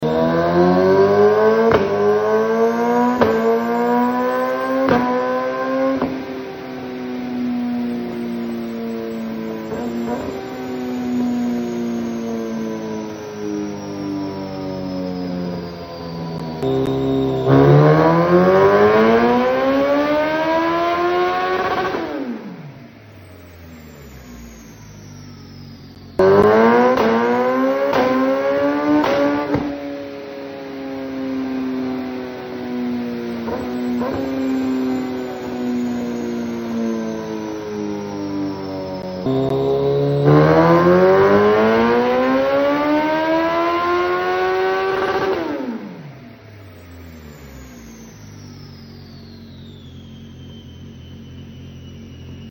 🇯🇵 GSX R Hayabusa 1300 Gen sound effects free download
(Remapping is immediately needed) We put the bike on the dyno and custom tuned the bike to release the most performance out of it!
Aggressive quickshifting , lots of torque, beast sound from that brocks!